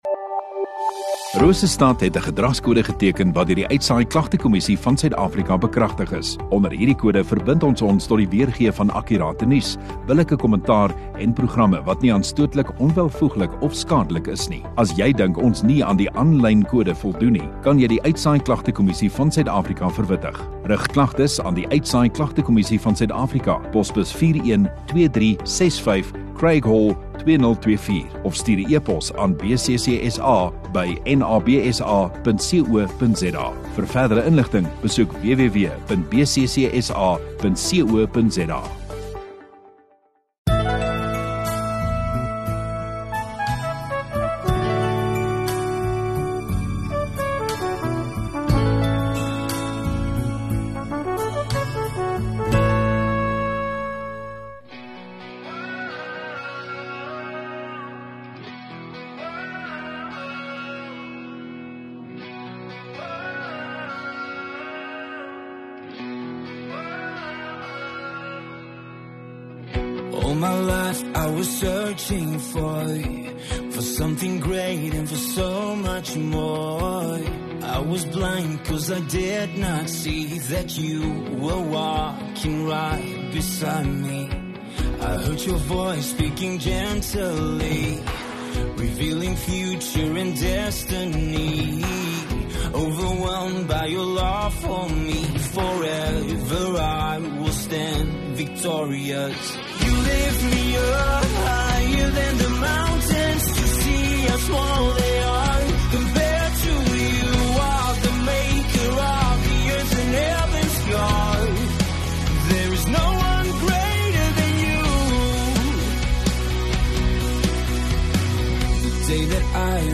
15 Oct Sondagaand erediens